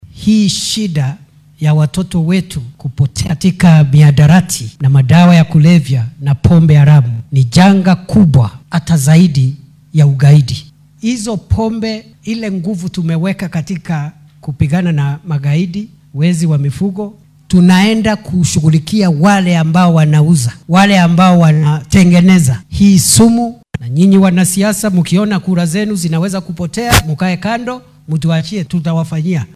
Kithure Kindiki oo arrimahan ka hadlay xilli uu ku sugnaa deegaanka Maragua ee ismaamulka Murang’a ayaa dhanka kale baadariyaasha kaniisadaha ugu baaqay inay dowladda garab ku siiyaan sidii meesha looga saari lahaa shakhsiyaadka faafinaya caqiidooyinka halista ah isagoo tusaale u soo qaatay meydadka laga helay keynta Shakahola ee ismaamulka Kilifi.